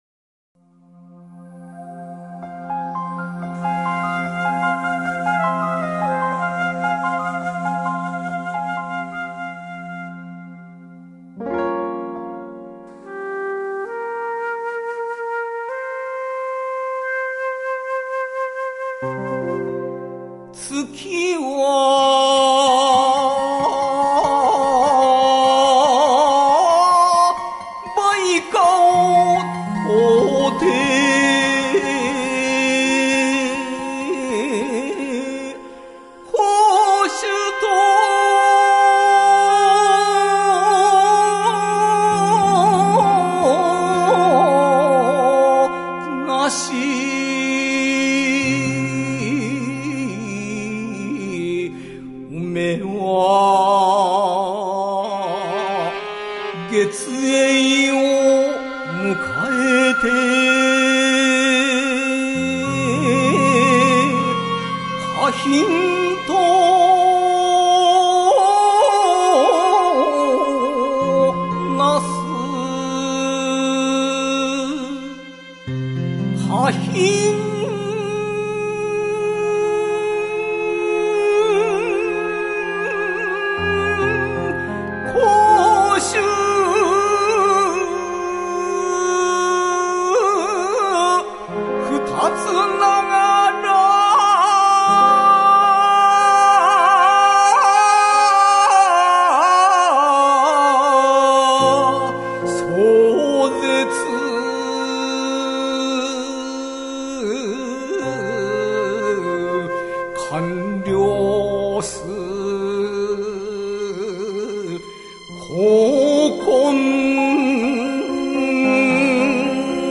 吟者